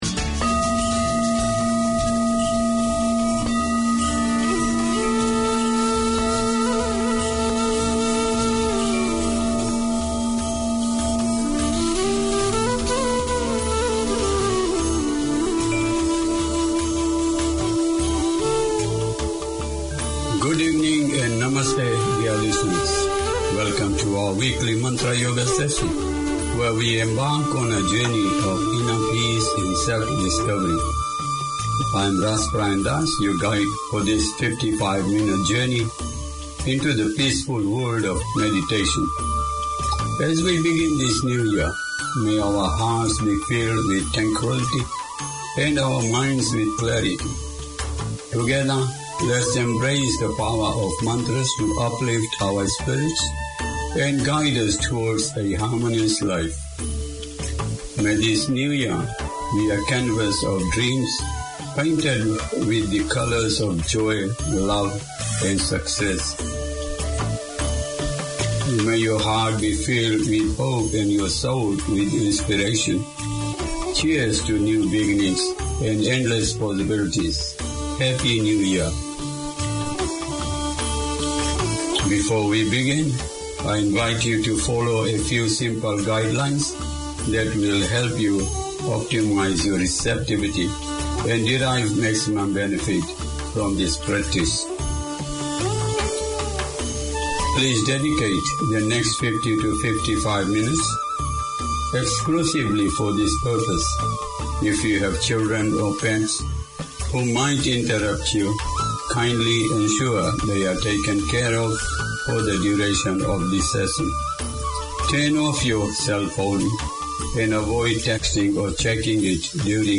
Community Access Radio in your language - available for download five minutes after broadcast.
Deco is the passion of the presenters of this programme that explores the local and global Deco scene, preservation and heritage, the buildings, jewellery and furnishings with interviews, music, notice of coming events; a sharing of the knowledge of Club Moderne, the Art Deco Society of Auckland.